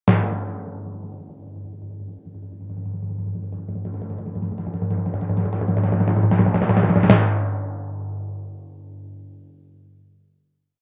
Ниже звуки ударов литавры с разной частотой и силой, которые вы можете послушать онлайн и загрузить на телефон, планшет или компьютер бесплатно.
4. Дробь на литавре для разных напряженных сцен в видео